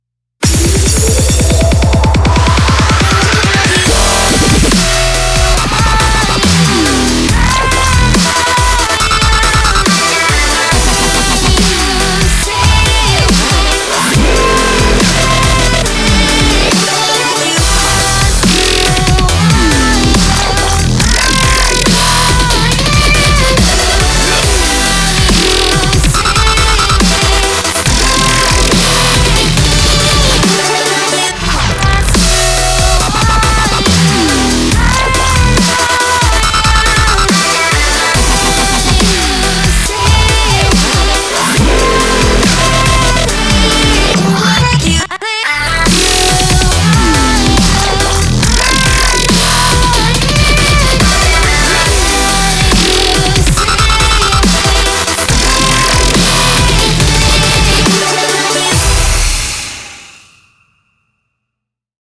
Dubstep short demo ver. 試聴用 Length: 1:02 minutes　bpm:140
ショートムービー向けにイントロ部分は短く、Chorus部分2回の構成のショート・デモとなっています。